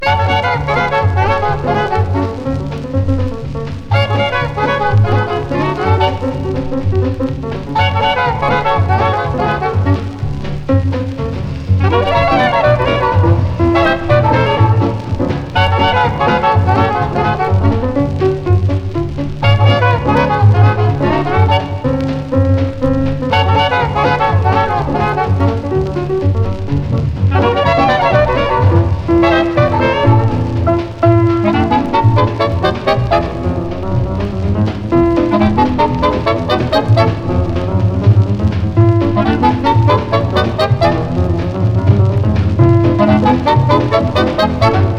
そんな1937年から1939年のニューヨークで夜な夜な炸裂したジャズの旨味がジューワーっと溢れ出してます。
Jazz　USA　12inchレコード　33rpm　Mono